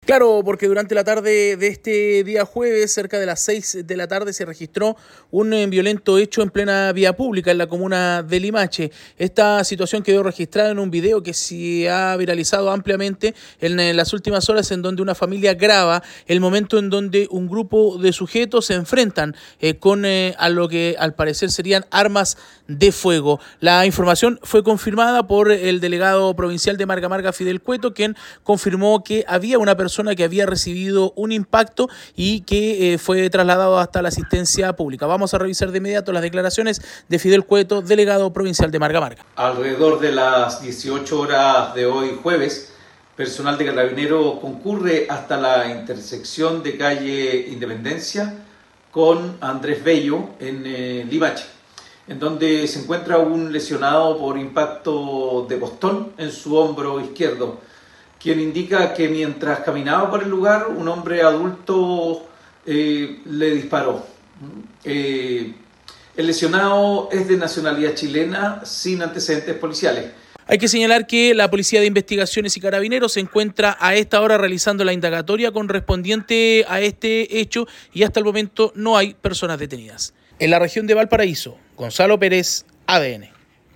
Graban violento tiroteo en la vía pública de Limache